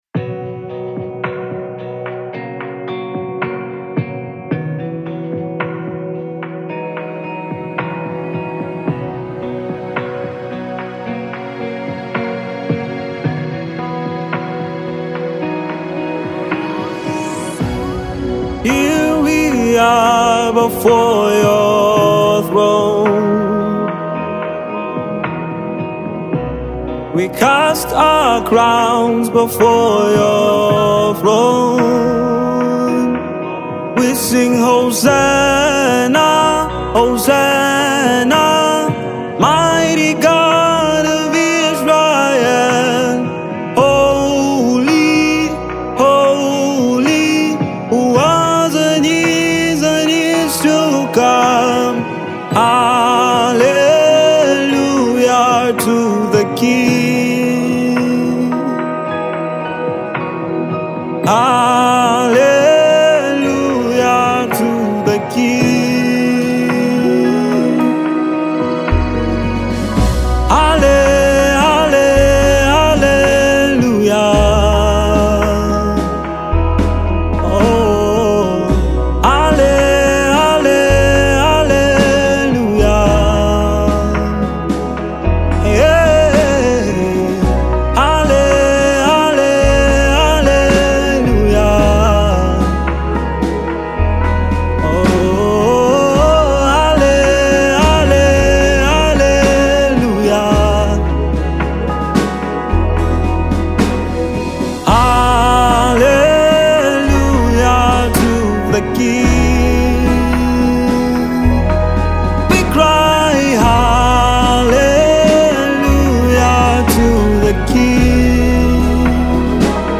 Naija Gospel Songs